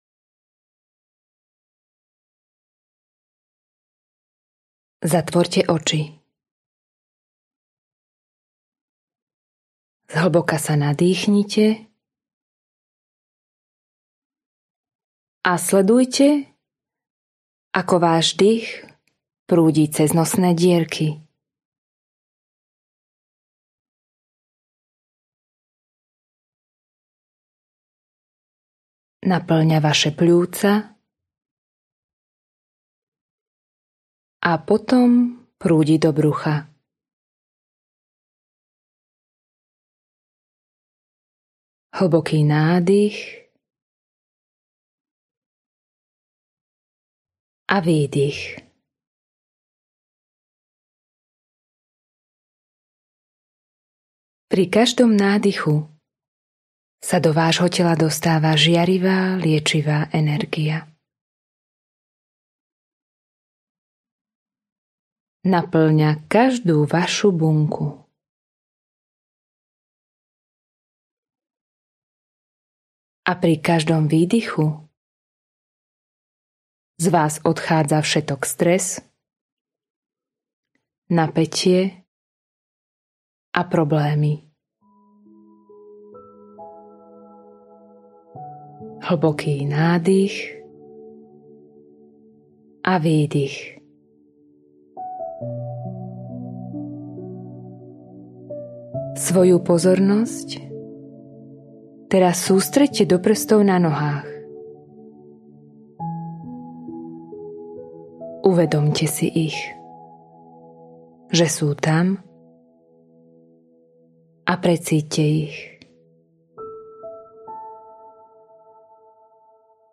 Liečivú meditáciu si môžeš stiahnuť tu: Meditácia - liečivý dych lásky Meditácia je úžasná vec!